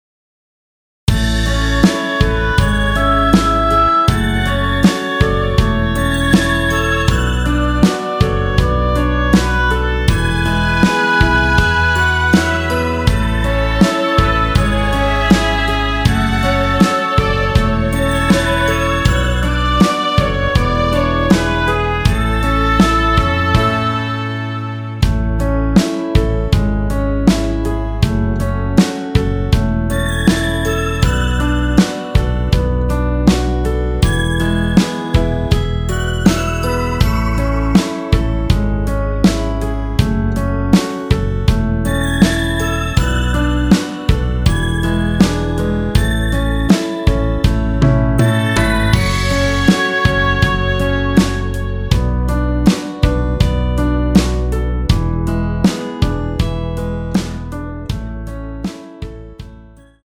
원키에서(+5)올린 MR입니다.
앞부분30초, 뒷부분30초씩 편집해서 올려 드리고 있습니다.
중간에 음이 끈어지고 다시 나오는 이유는